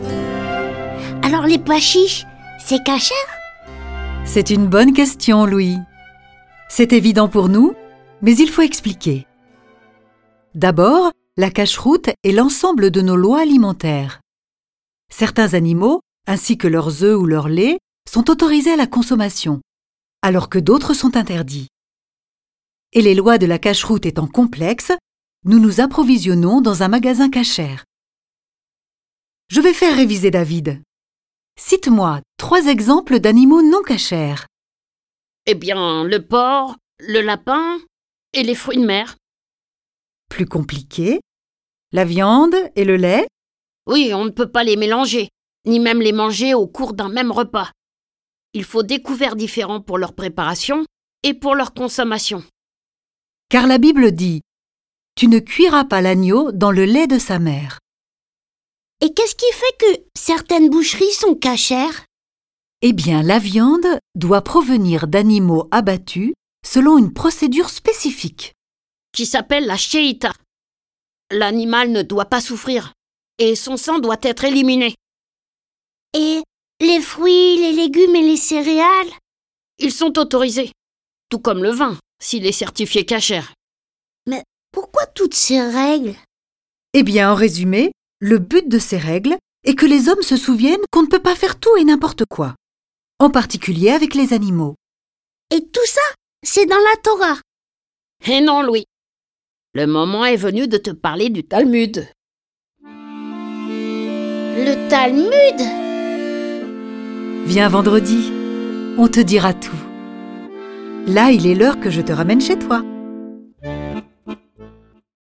Diffusion distribution ebook et livre audio - Catalogue livres numériques
Avec ce récit, animé par 4 voix et accompagné de plus de 30 morceaux de musique classique et traditionnelle, vous comprendrez la signification des mots bar-mitsva, kippa, Torah, Talmud, ashkénaze, sépharade, Yiddish, Shabbat, casher, Rosh Hashana, Yom Kippour et bien d’autres. Le récit et les dialogues sont illustrés avec les musiques de Bloch, Corelli, Debussy, Fauré, Grieg, Liszt, Monti, Mozart, Rimski-Korsakov, Schubert, Tchaikovski, Telemann, Vivaldi et des musiques traditionnelles.